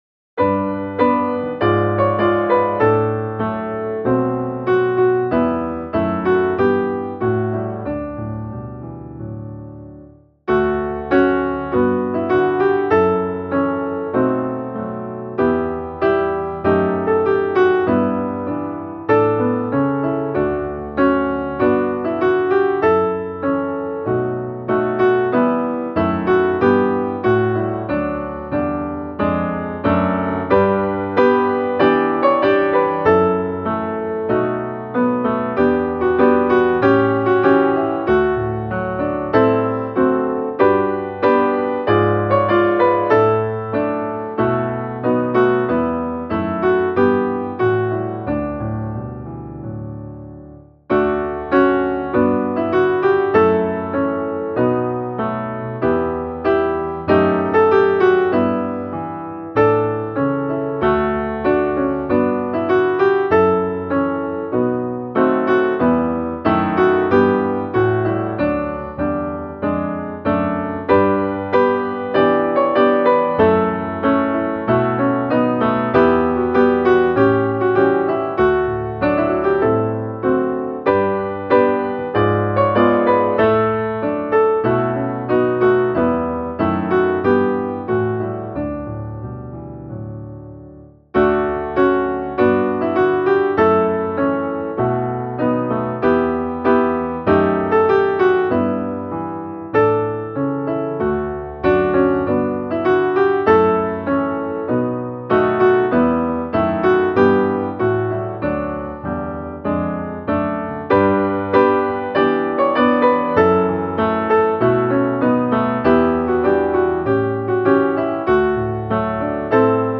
O, hur saligt att få vandra - musikbakgrund
Musikbakgrund Psalm